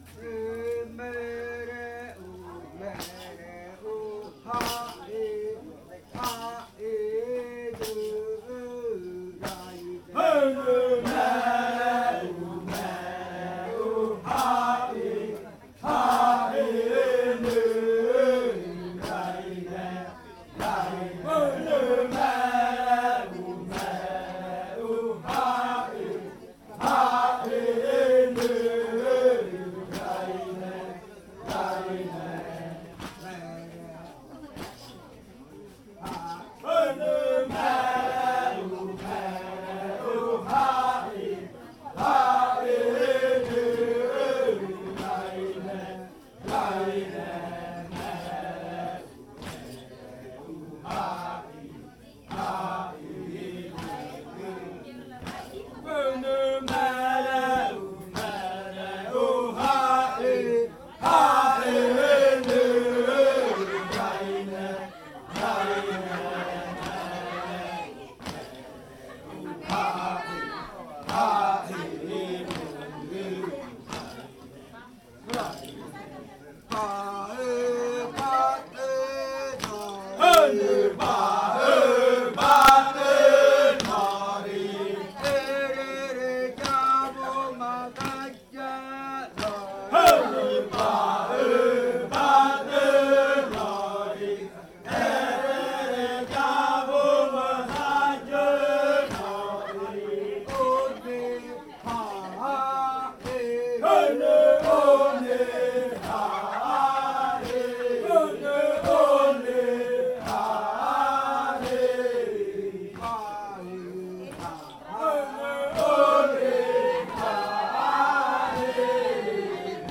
Canto de la variante jaiokɨ
Leticia, Amazonas
con el grupo de cantores bailando en la Casa Hija Eetane.
with the group of singers dancing at Casa Hija Eetane.